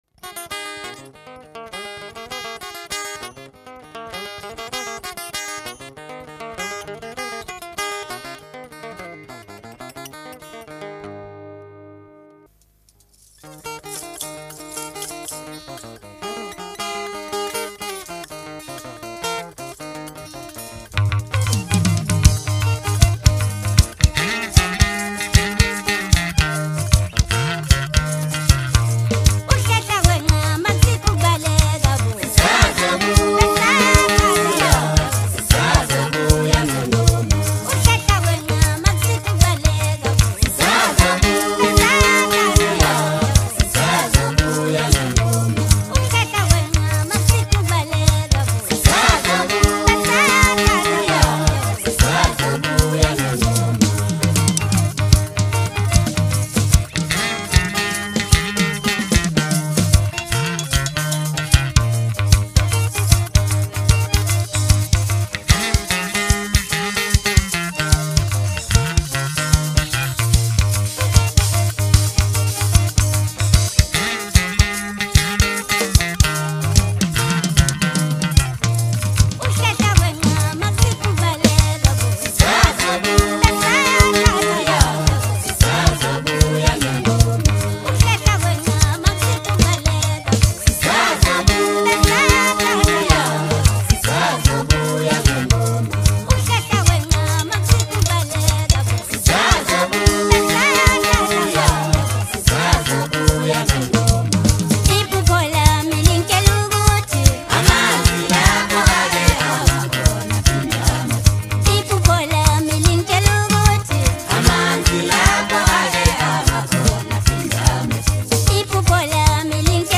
Maskandi-infused masterpiece